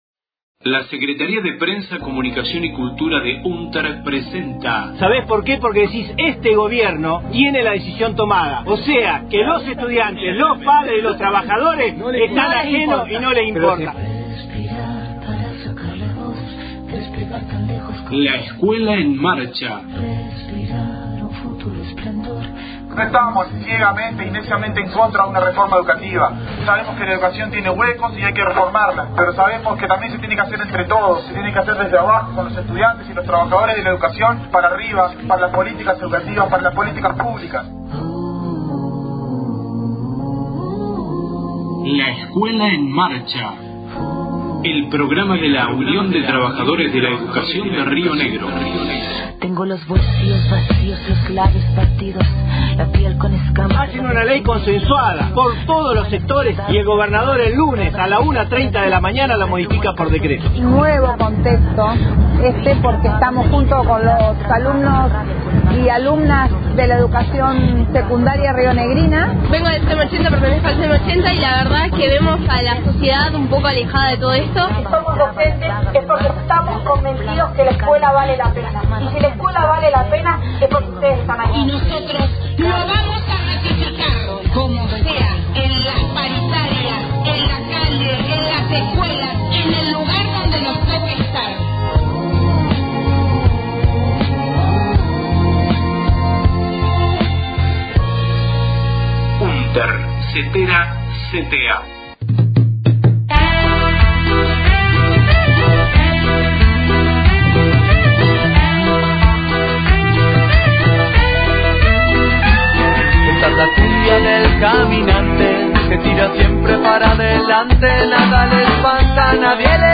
LEEM, 29/08/18 Quince mil trabajadores marcharon por la capital provincial para poner freno al avance de los derechos conquistados. 28 A: Histórica y masiva movilización en Viedma el marco del paro del Frente Sindical y Social Rionegrino. Voces de la movilización